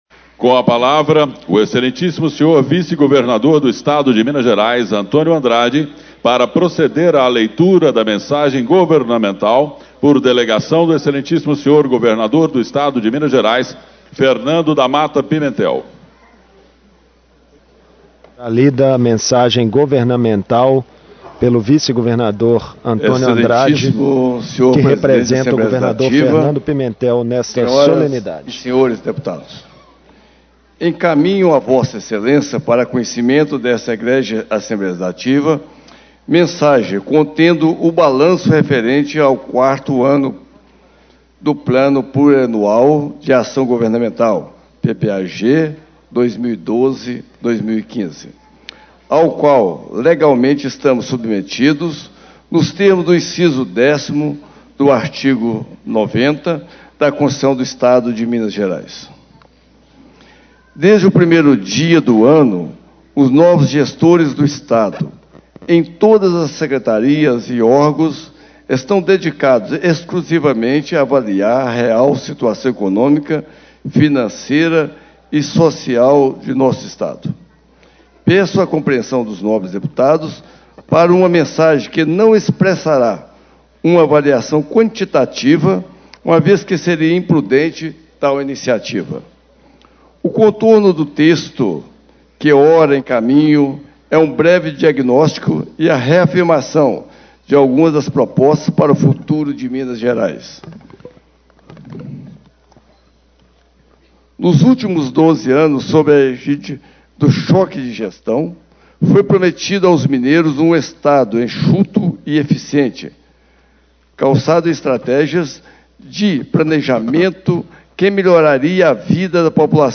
Antônio Andrade, PMDB - Vice-Governador do Estado de Minas Gerais - Leitura da Mensagem Governamental por delegação do Governador Fernando Damata Pimentel - PT
Reunião Preparatória de Posse dos deputados da 18ª Legislatura